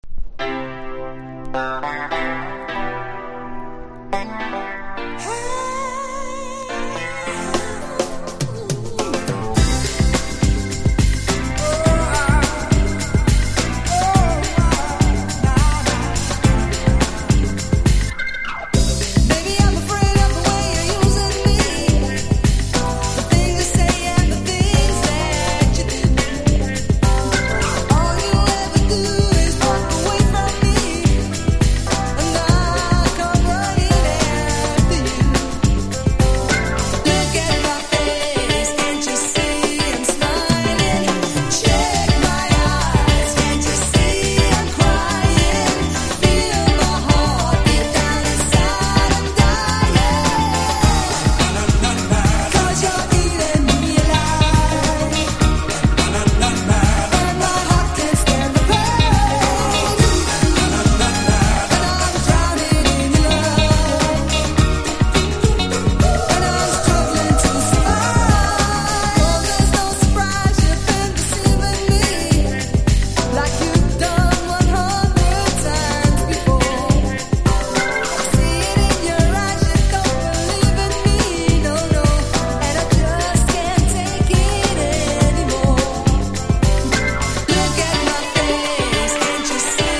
UK SOUL〜ACID JAZZ期のグループ。